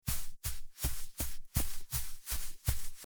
Footsteps On Grass
Footsteps_on_grass.mp3